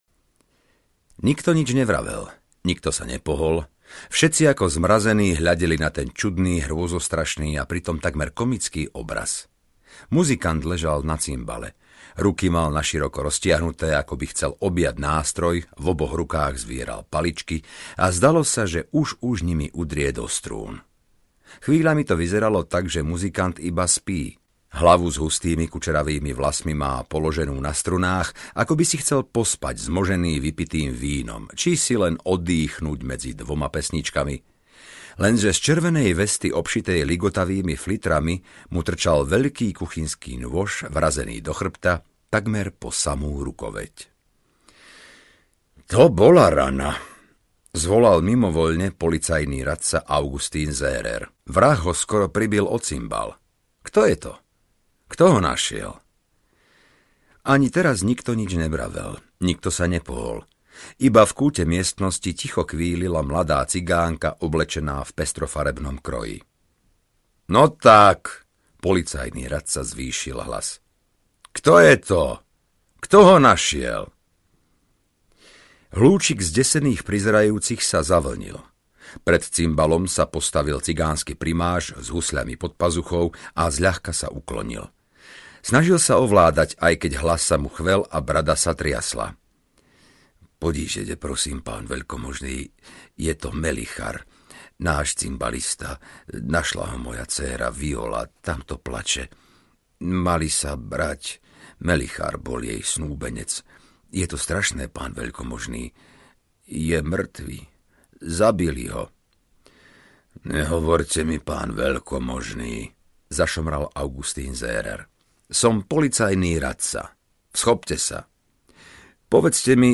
Čierny chlieb lásky audiokniha
Ukázka z knihy